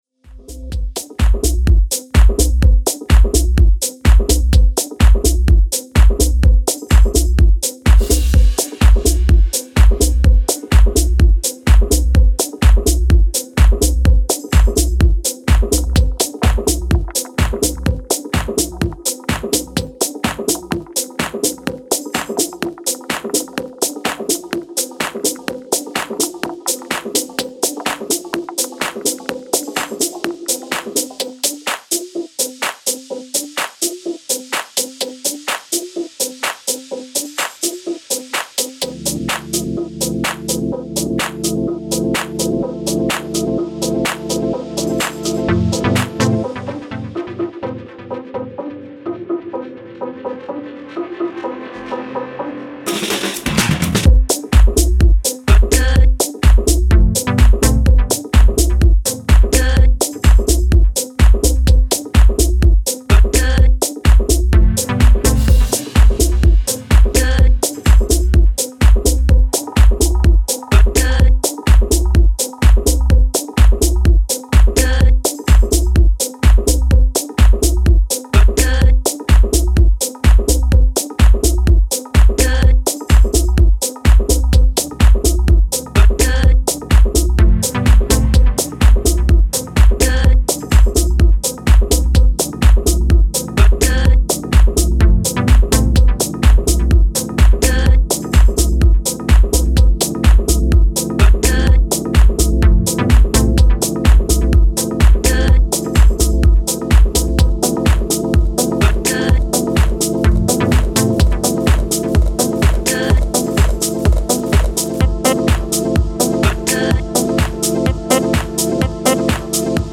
Style: House / Tech House